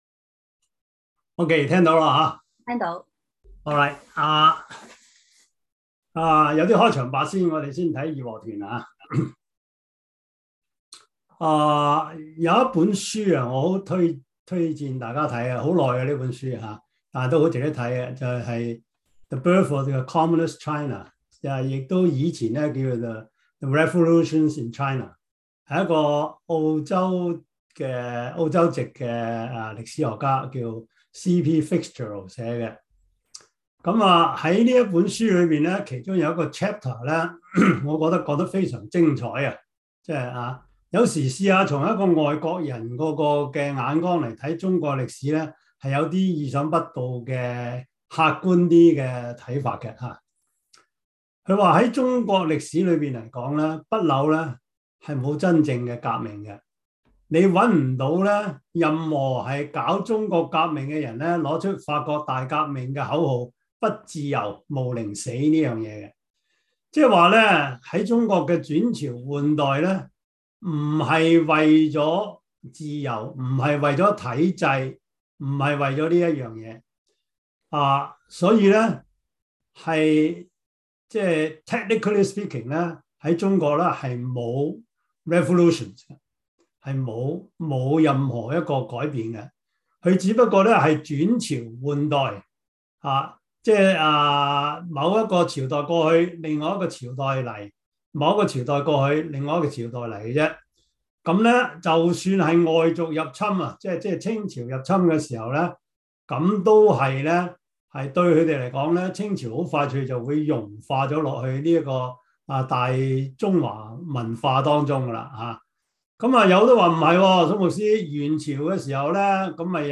Service Type: 中文主日學